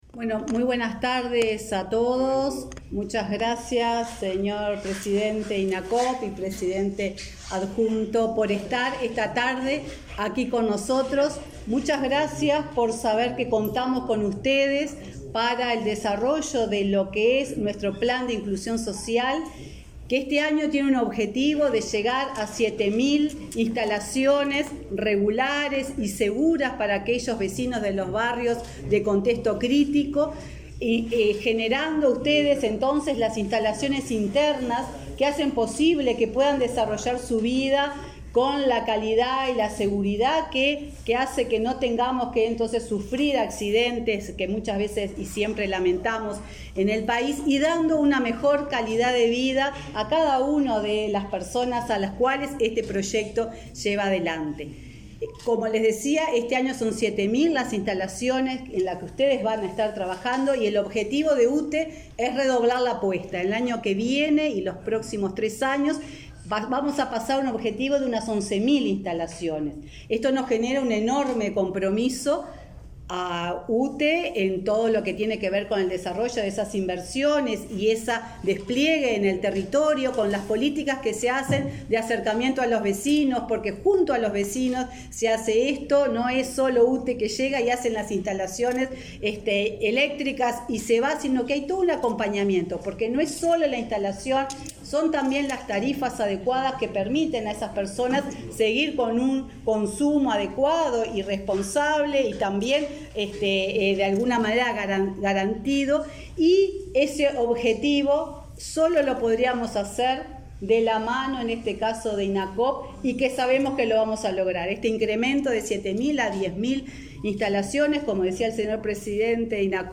Conferencia de prensa por convenio entre UTE e Inacoop para regularización de servicios
Conferencia de prensa por convenio entre UTE e Inacoop para regularización de servicios 28/09/2021 Compartir Facebook X Copiar enlace WhatsApp LinkedIn La Administración Nacional de Usinas y Trasmisiones Eléctricas (UTE) y el Instituto Nacional del Cooperativismo (Inacoop) firmaron un convenio para regularizar instalaciones eléctricas de los hogares más vulnerables. Participaron la presidenta de UTE, Silvia Emaldi, y el titular de Inacoop, Martín Fernández.